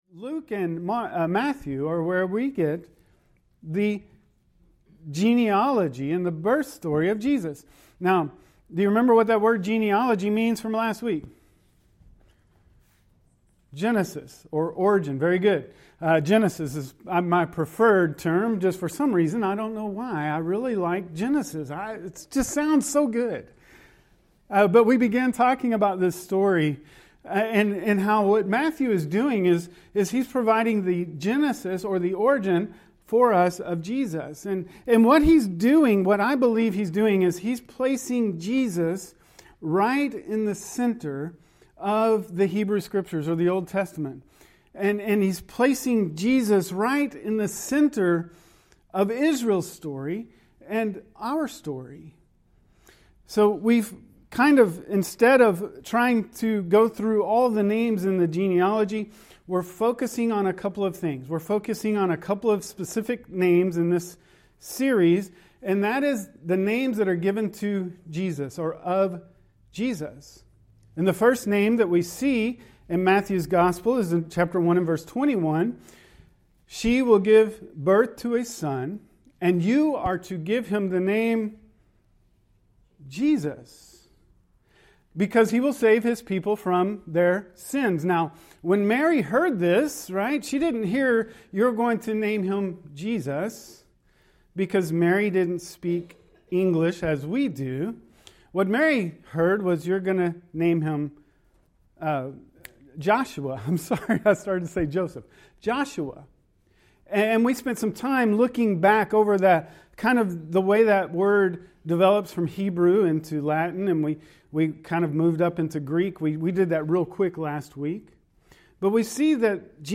A GENESIS STORY (pt. 2) lesson audio From the first chapters of Matthew's gospel, we are considering the birth of Jesus and the story of Jesus.